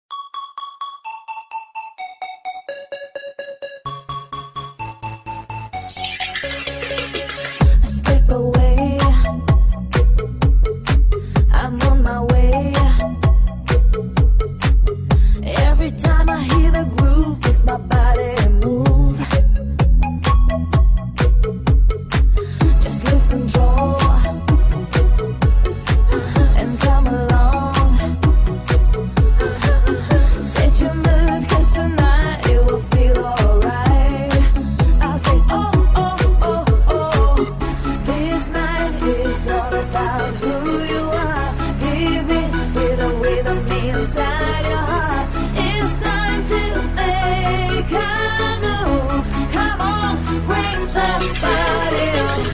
• Качество: 128, Stereo
поп
громкие
женский вокал
dance
электронная музыка
красивый женский голос